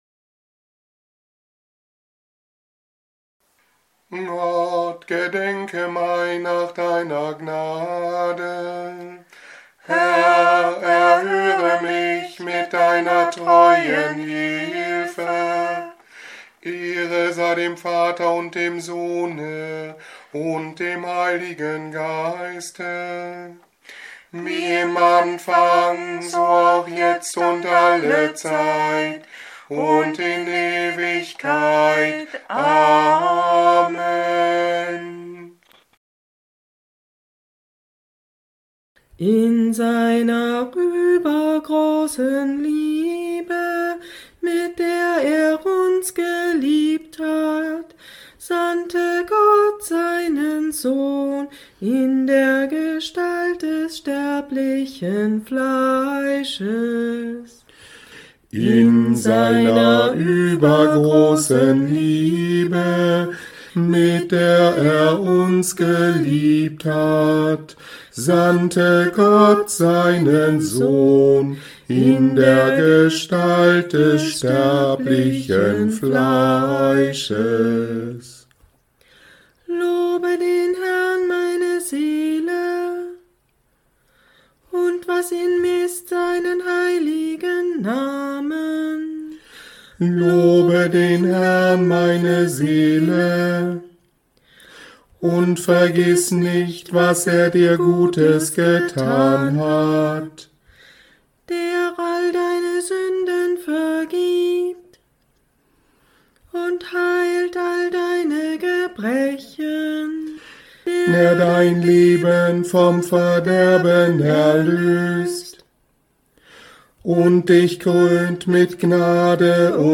Stundengebete in der Woche nach dem Sonntag Judica 2026